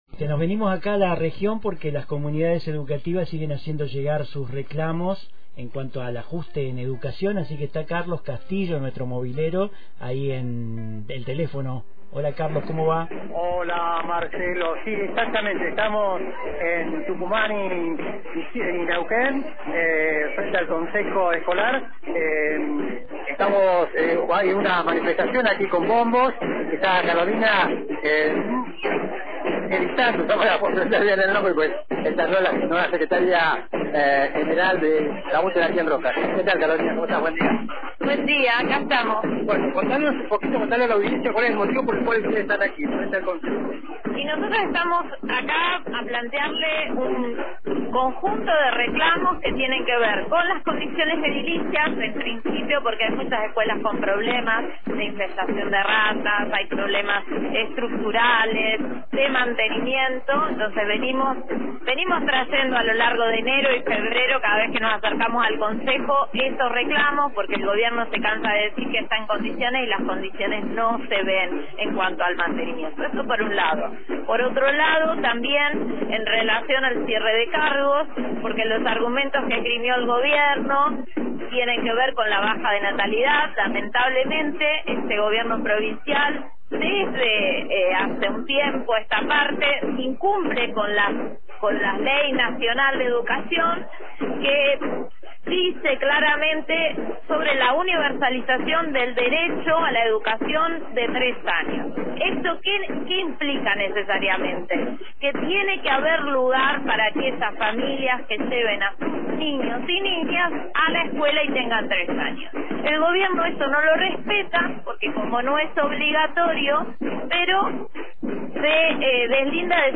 Miembros de la comunidad educativa se movilizaron frente al Consejo Escolar en rechazo a los ajustes en educación y el cierre de cargos docentes. La protesta, acompañada por bombos y cánticos, se desarrolló sin presencia policial y con la participación de docentes activos y jubilados.